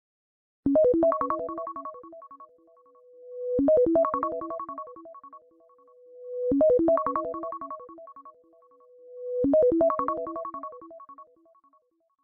Nhạc Chuông Báo Thức